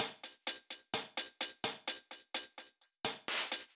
Index of /musicradar/french-house-chillout-samples/128bpm/Beats
FHC_BeatB_128-01_Hats.wav